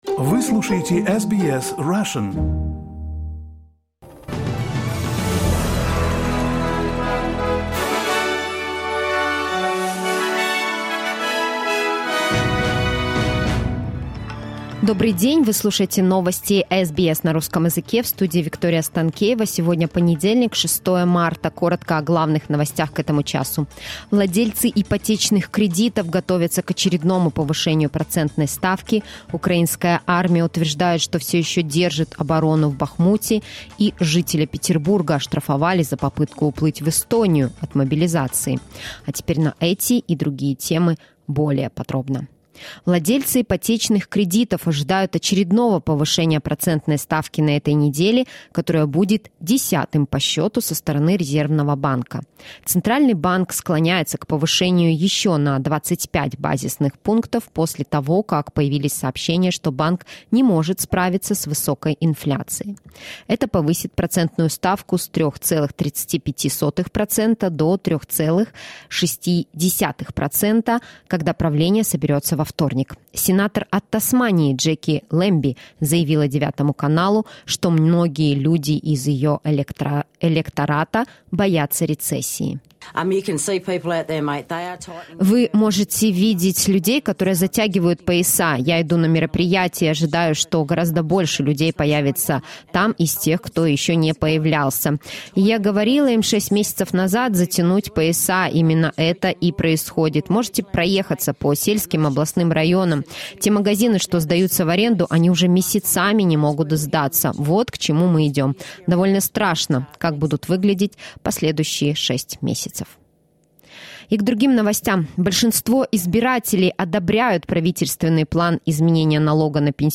SBS news in Russian — 06.03.2023